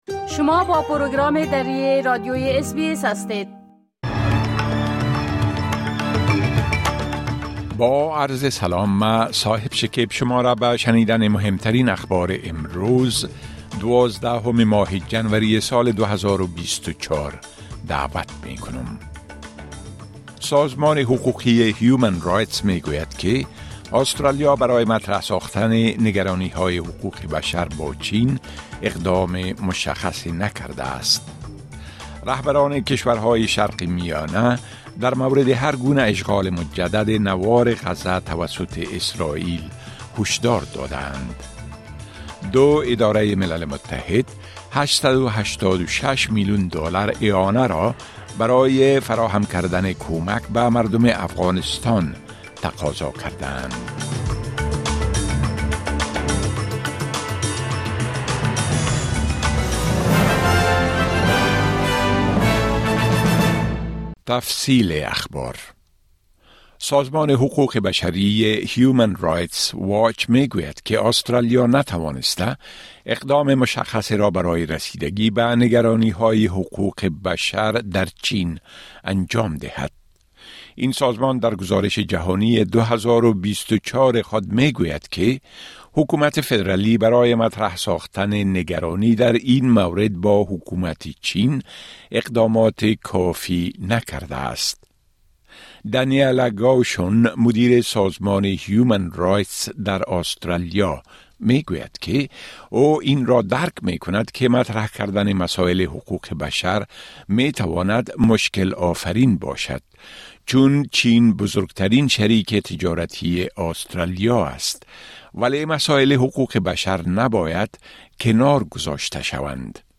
گزارش رويدادهاى مهم اخير از برنامۀ درى راديوى اس بى اس
گزارش رويدادهاى مهم اخير به زبان درى از راديوى اس بى اس را در اينجا شنيده مى توانيد.